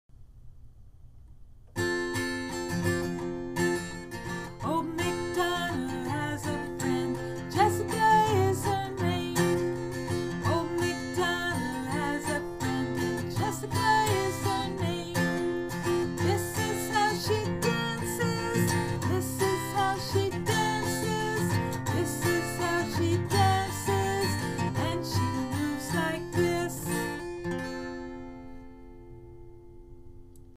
DOMAIN(S) Physical Development and Motor Skills (PDM) INDICATOR(S) PDM3.4a Tune: “Old MacDonald” Old MacDonald has a friend And (child’s name) is her name.